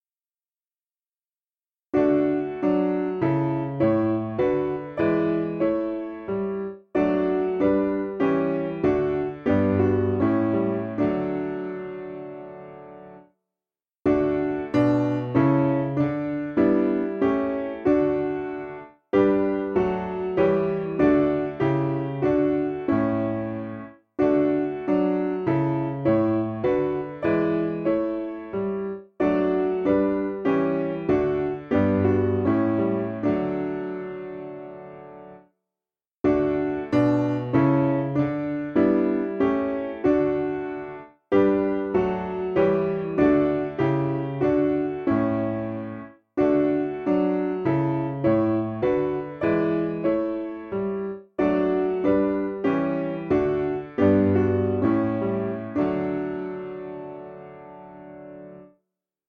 Information about the hymn tune BOYCE.